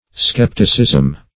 Skepticism \Skep"ti*cism\, n. [Cf. F. scepticisme.] [Written